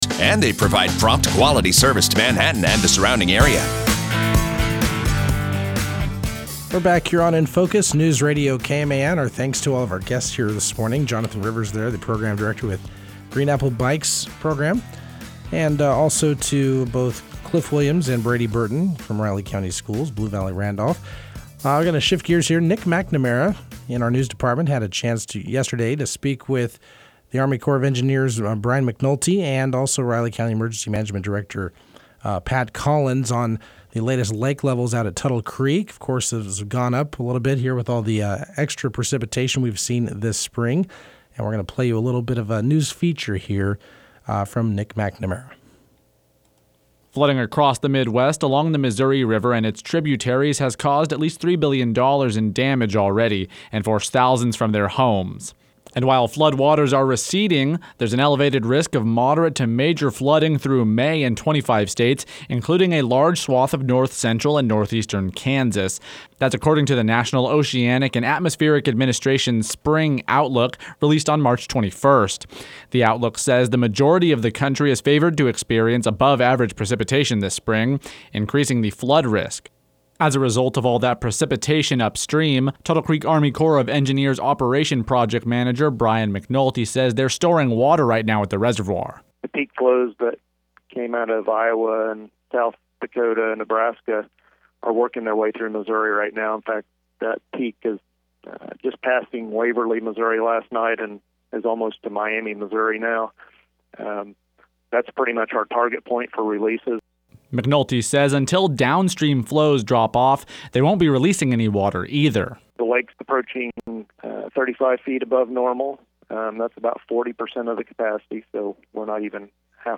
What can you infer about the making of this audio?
a previously recorded phone interview